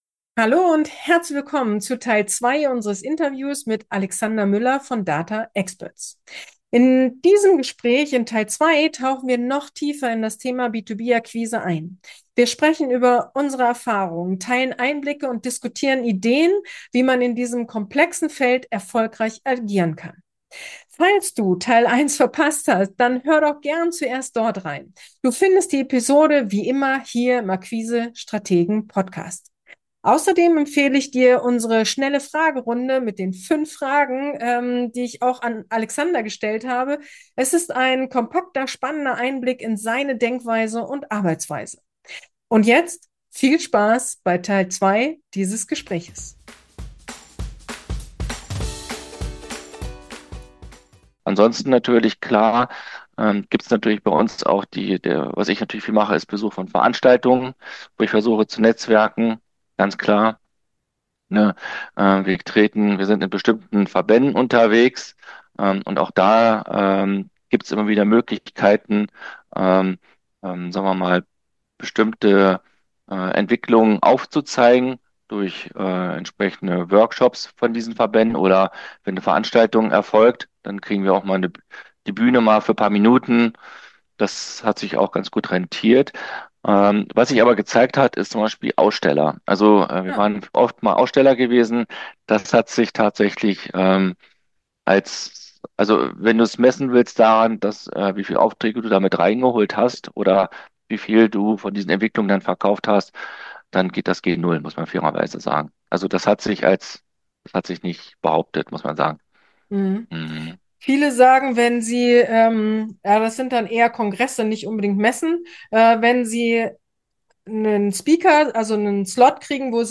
B2B-Vertrieb in der Praxis: Interview